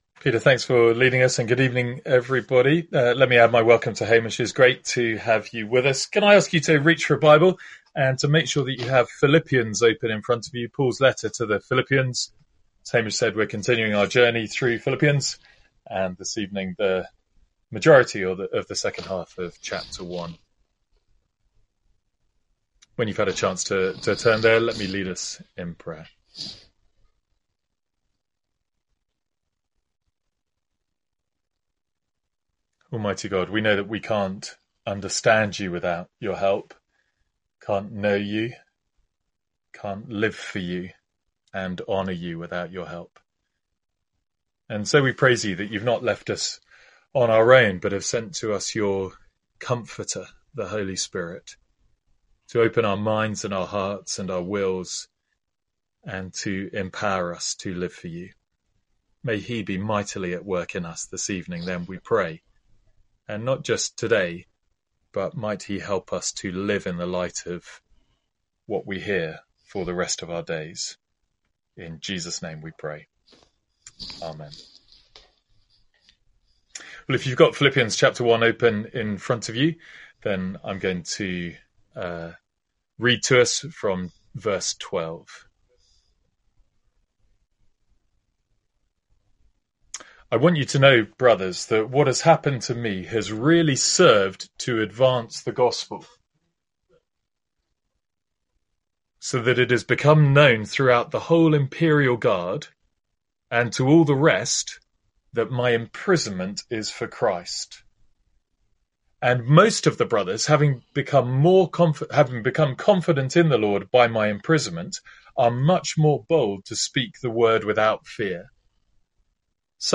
From our morning service in Philippians.